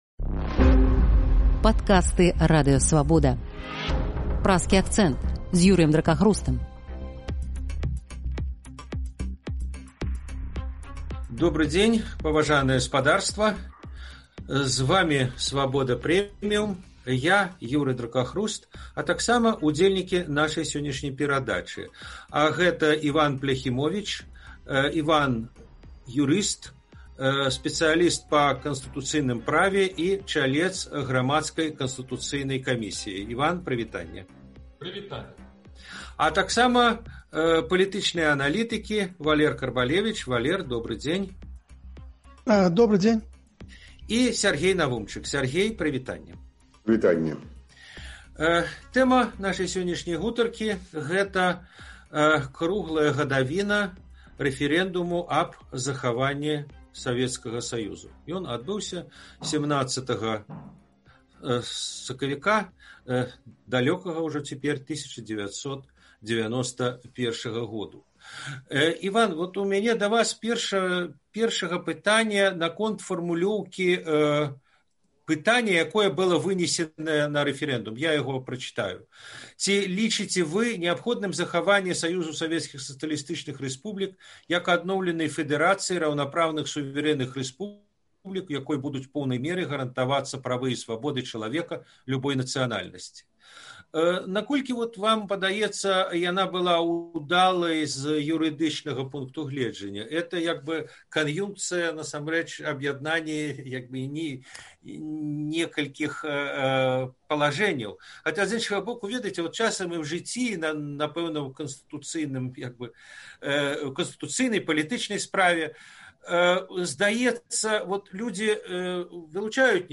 Гэтыя тэмы ў Праскім акцэнце абмяркоўваюць юрыст